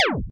laserSmall_003.ogg